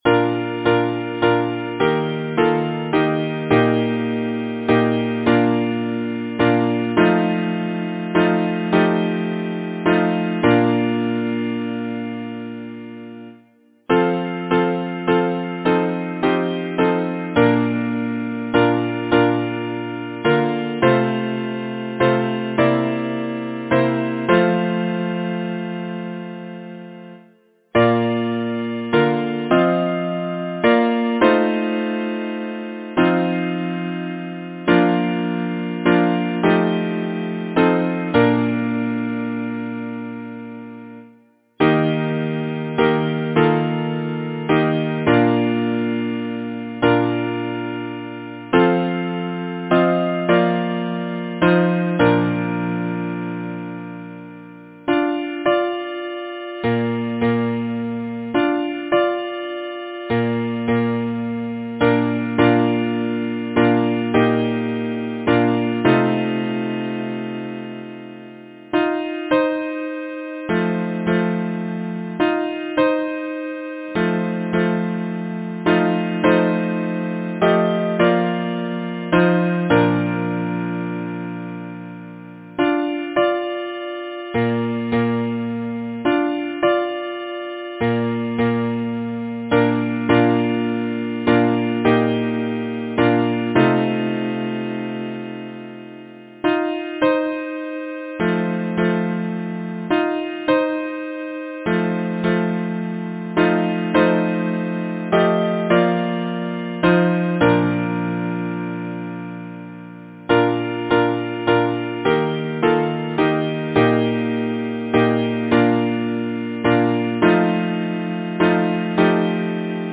Title: The Bells of Eventide Composer: Hugh Henry McGranahan Lyricist: Ada Blenkhorn Number of voices: 4vv Voicing: SATB Genre: Secular, Partsong
Language: English Instruments: A cappella